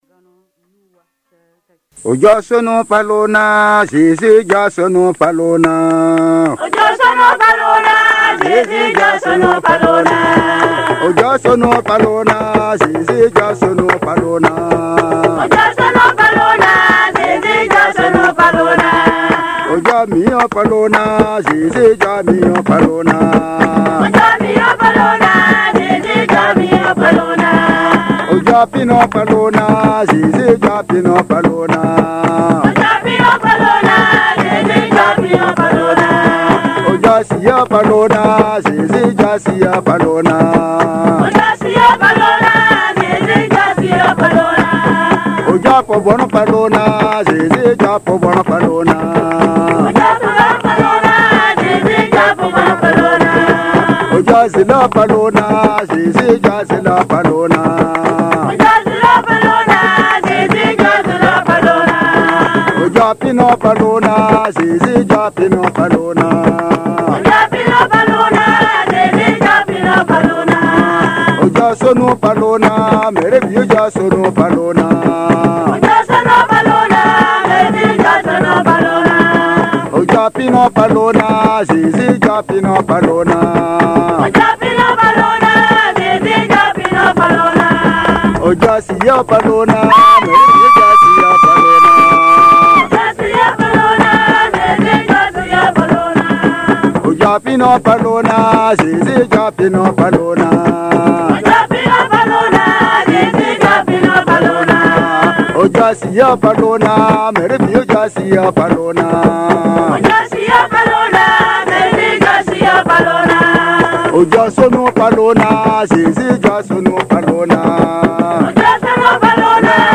Ces chants ont été enregistrés lors d'un atelier ethno-art organisé par l'équipe de traduction de la Bible en Nuni du sud. Les artisites sont des choristes venus de plusieurs églises de la région de Sapouy.